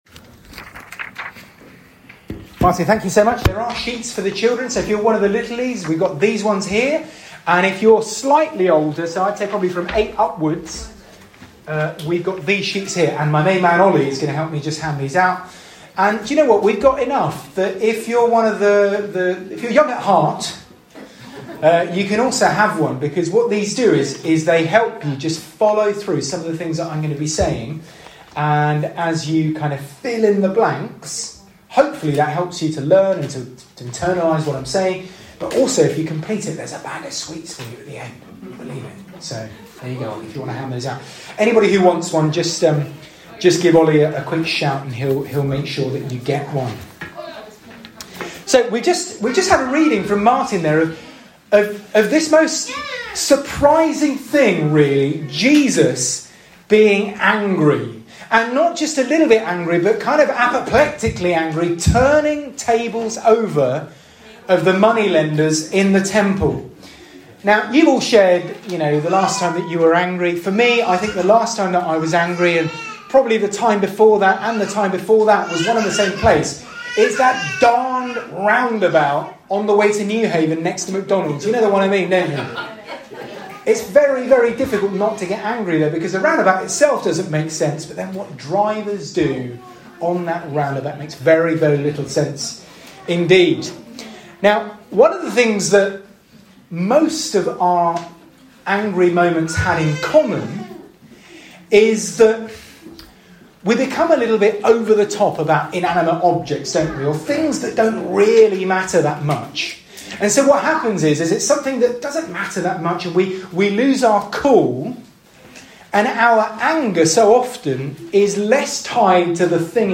SERMON-9TH-FEBRUARY.mp3